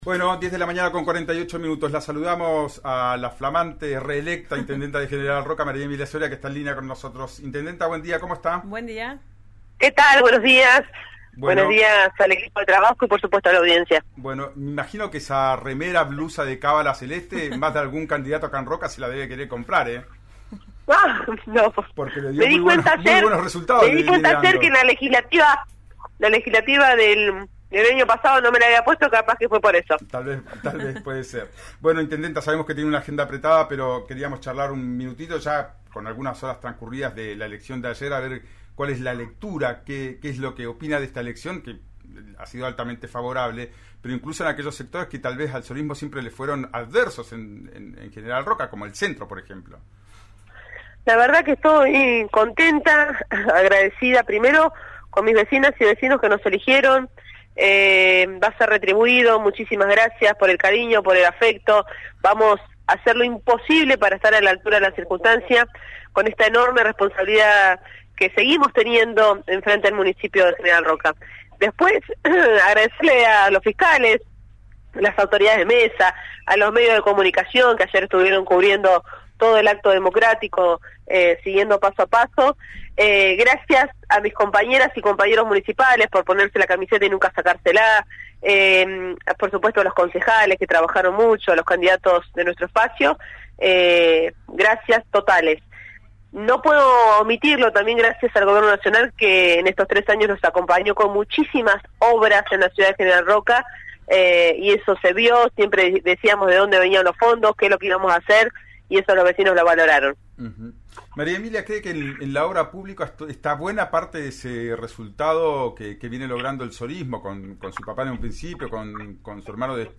La intendenta de General Roca habló con RÍO NEGRO RADIO y expresó su satisfacción por el resultado en las urnas de ayer. Se refirió al tema de las colectoras y a las expectativas para las elecciones provinciales de abril.